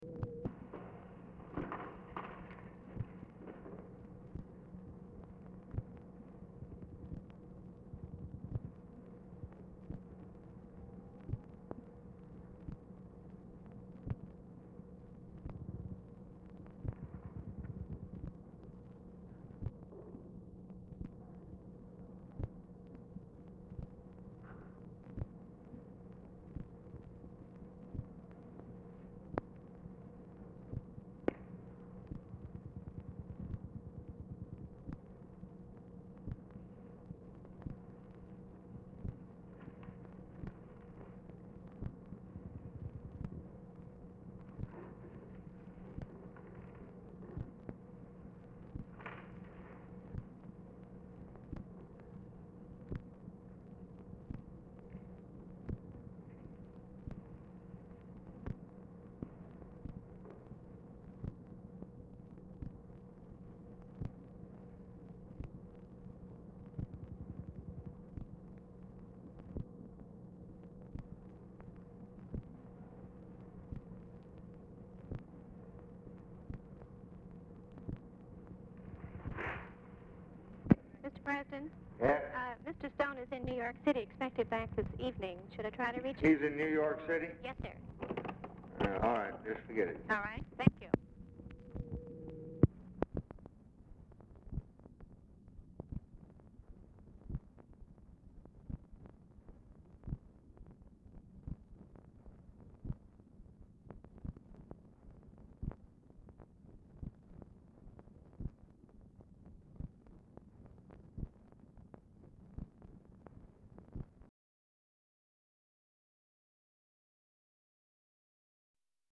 Format Dictation belt
Location Of Speaker 1 Oval Office or unknown location
Speaker 2 TELEPHONE OPERATOR Specific Item Type Telephone conversation